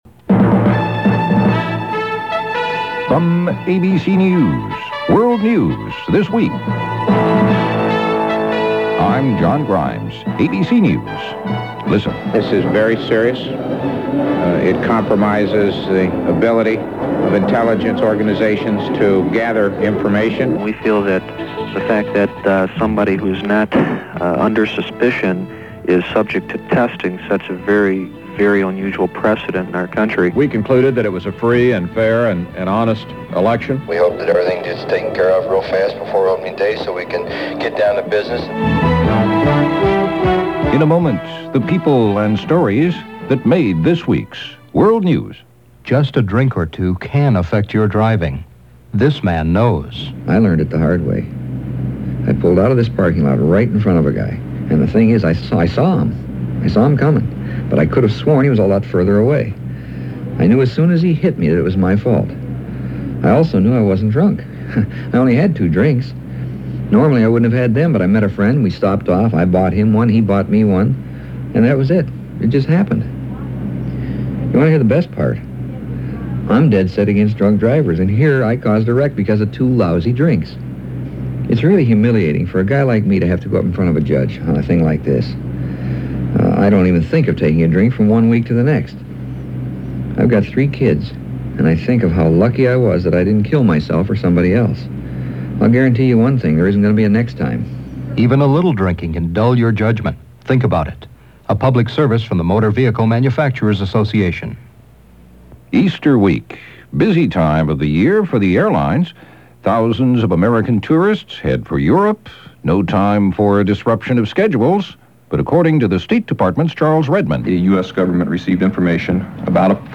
– ABC World News This Week – Week Ending March 26, 1989 – Gordon Skene Sound Collection
Not having anything to do with the War on Drugs, but having a lot to do with impaired judgment based on being drunk, news of the oil spill in Alaska from the Exxon Valdez made news buried somewhere towards the end of this half-hour long newscast.